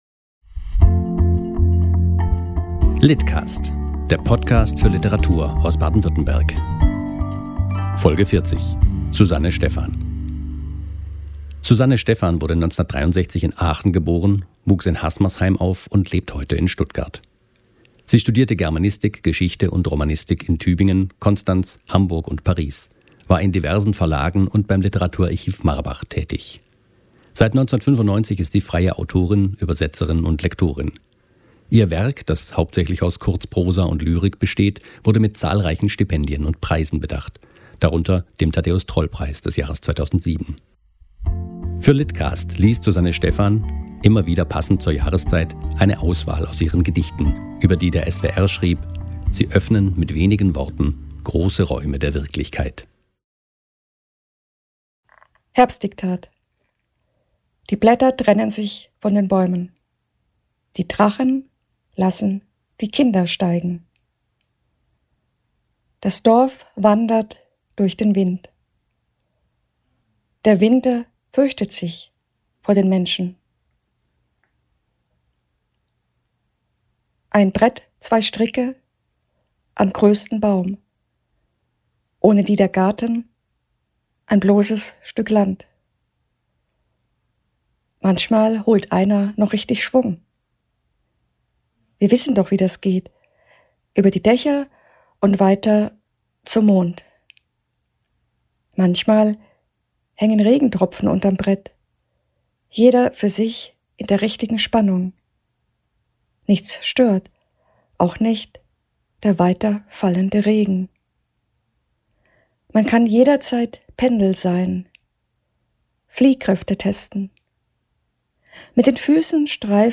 liest aus ihren Gedichten